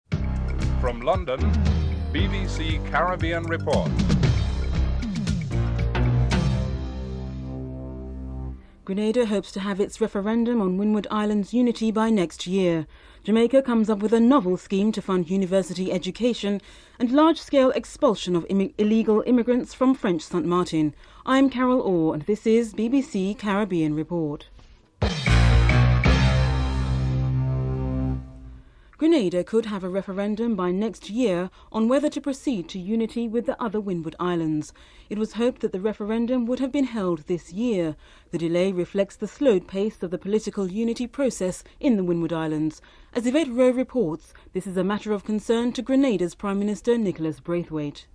1. Headlines (00:00-00:35)
Interview with Tony Blair, Labour Spokesman on Home Affairs.